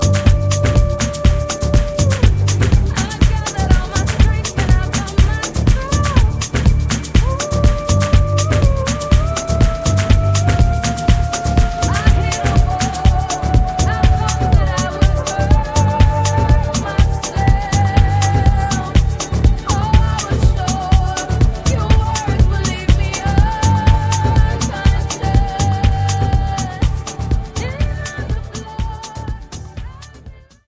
REMIX TRACKS